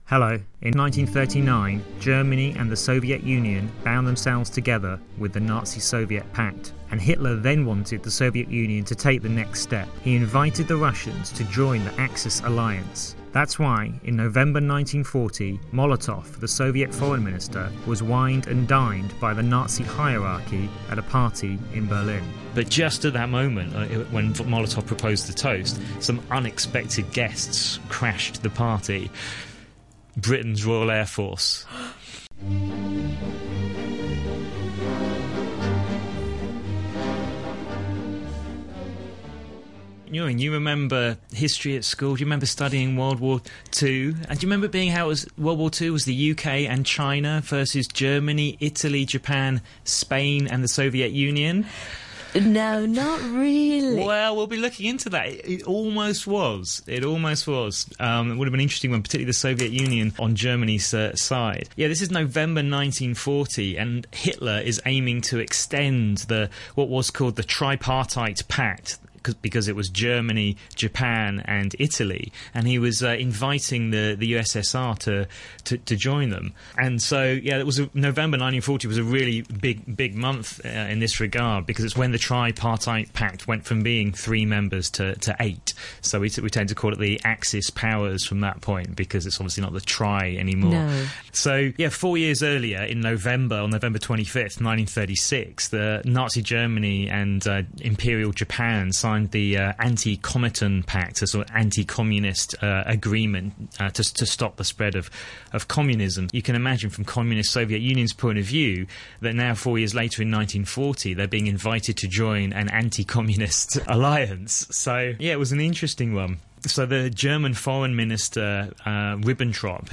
Recorded at Radio Television Hong Kong Studios, Broadcast Drive, Kowloon, Hong Kong.